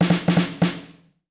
British SKA REGGAE FILL - 12.wav